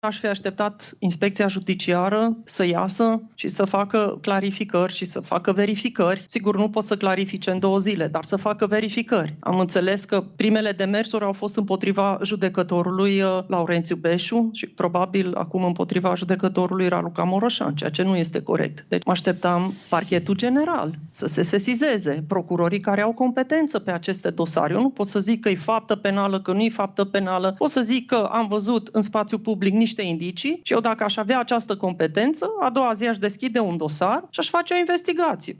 Parchetul General și Inspecția Judiciară ar fi trebuit să investigheze suspiciunile rezultate în urma documentarului Recorder, consideră Laura Codruța Kovesi. Șefa Parchetului European a spus într-un interviu pentru Friendly Fire, podcastul realizat de Vlad Petreanu și Moise Guran că a fost șocată de reacția autorităților române.